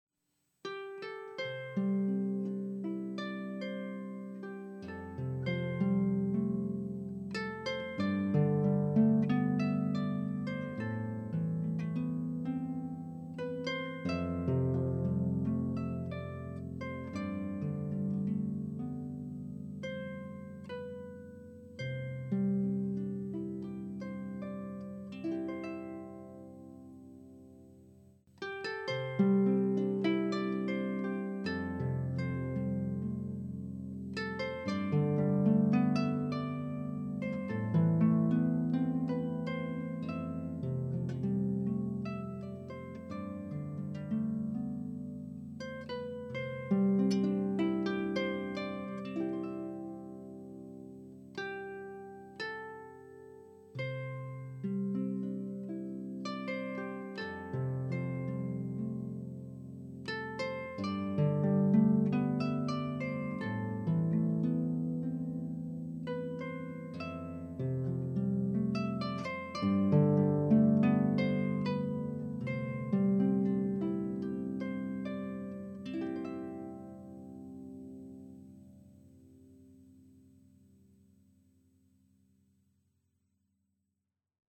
Audio #5 Listen to how I am playing this tune using rubato to express a different mood each of the three times through.
rubato.mp3